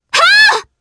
Frey-Vox_Attack4_jp.wav